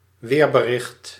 Ääntäminen
Ääntäminen Tuntematon aksentti: IPA: /ʋe:ɾ.bə.ɾɪχt/ Haettu sana löytyi näillä lähdekielillä: hollanti Käännös Substantiivit 1. météo {f} Muut/tuntemattomat 2. bulletin météorologique {m} Suku: n .